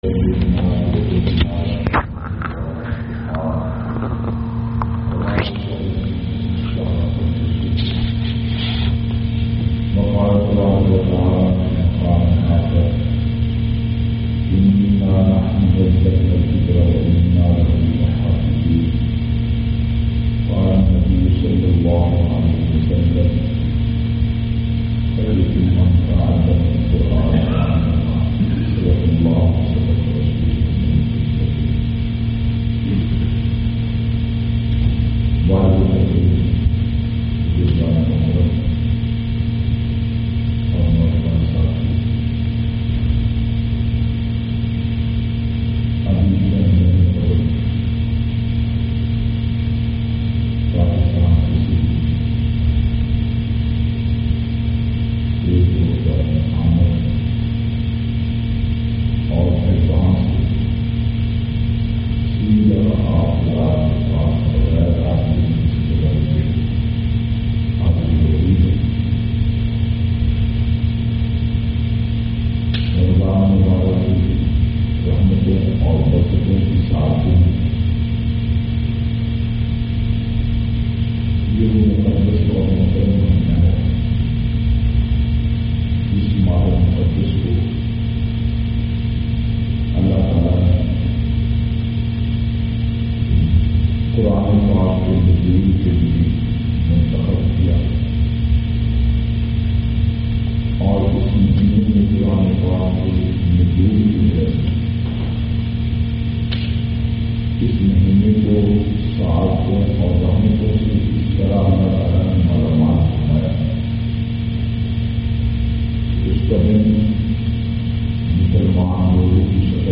661- Azmat e Quran Sadam Masjid-Bartanian,UK.mp3